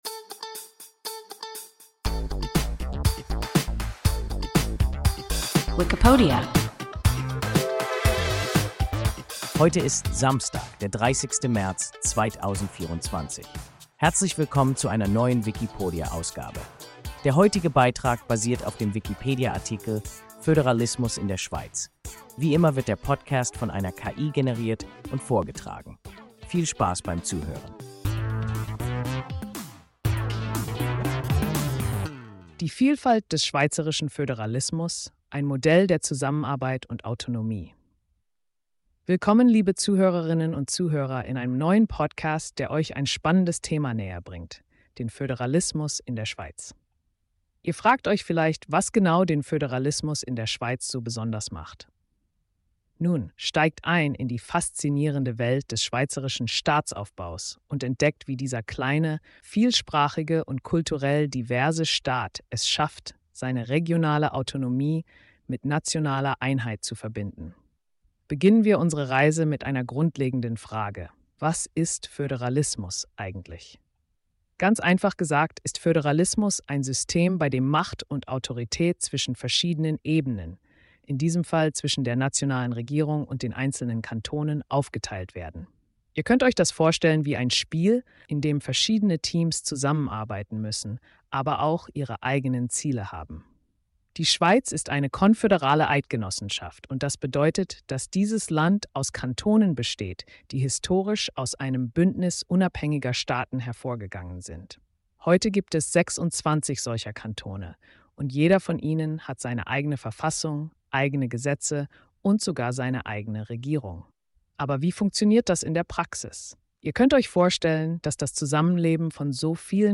Föderalismus in der Schweiz – WIKIPODIA – ein KI Podcast